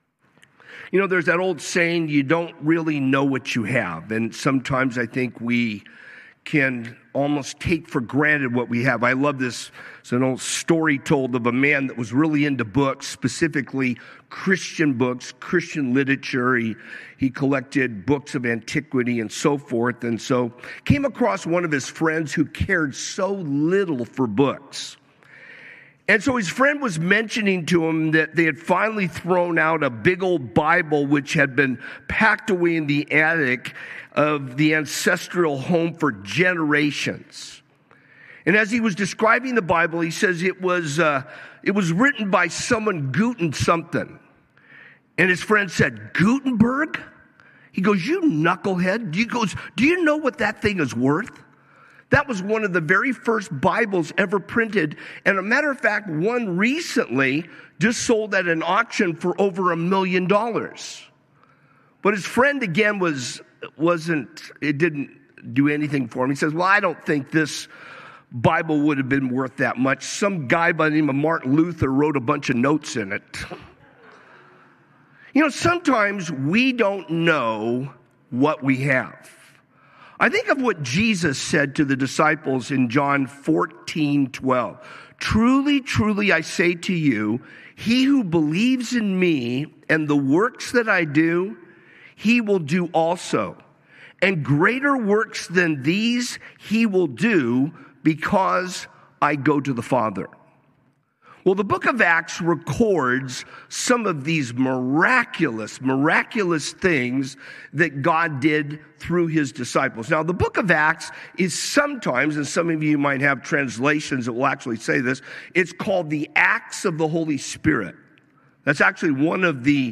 A message from the series "God At Work."